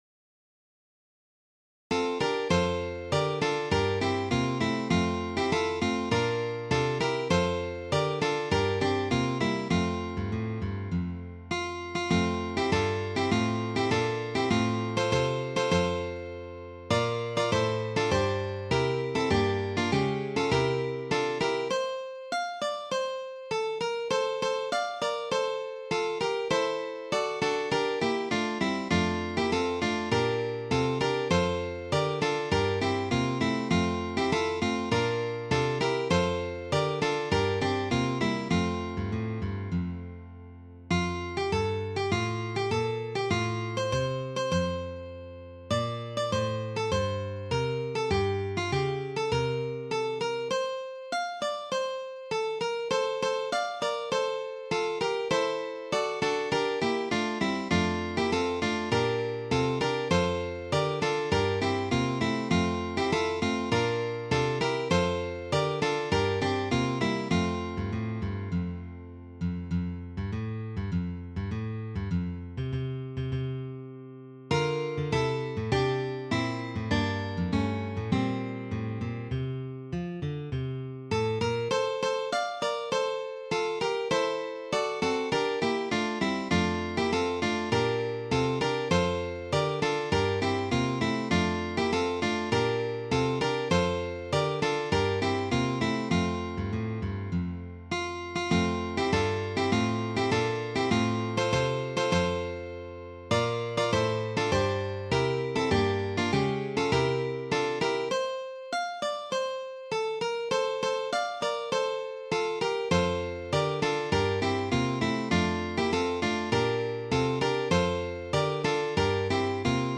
Guitar 1 should be played in tenth position.